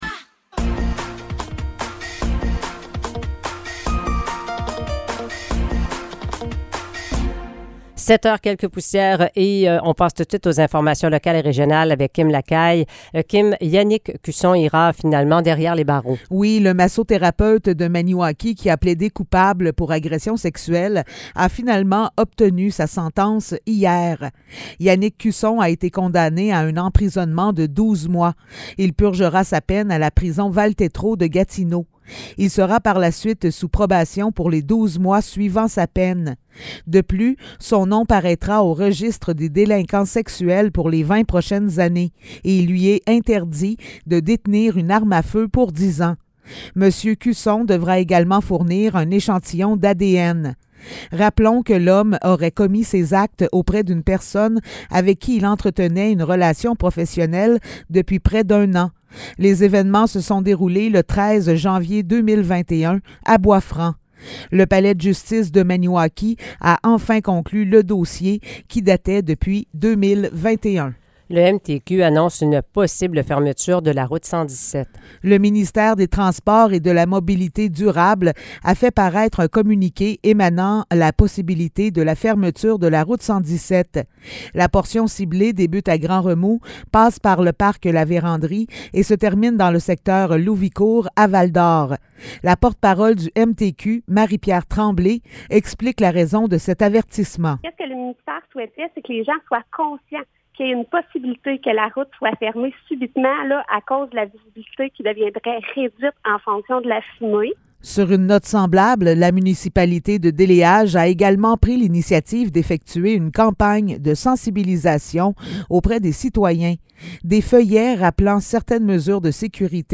Nouvelles locales - 9 juin 2023 - 7 h